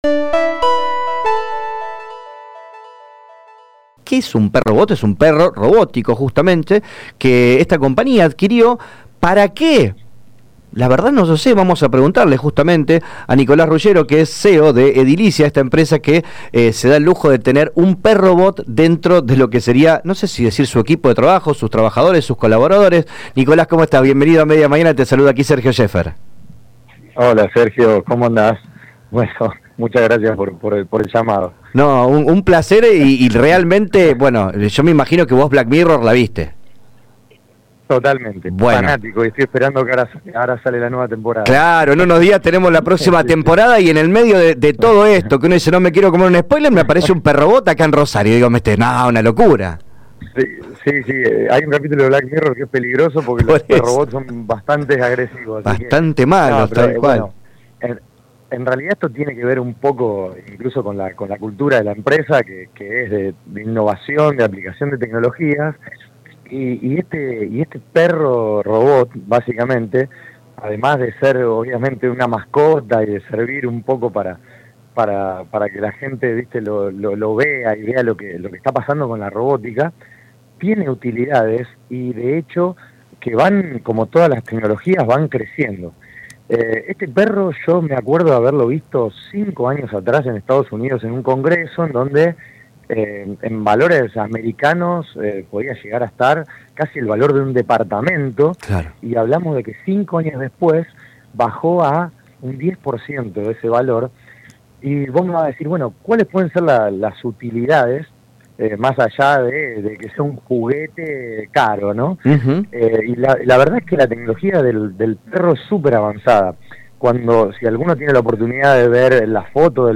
en diálogo con LT3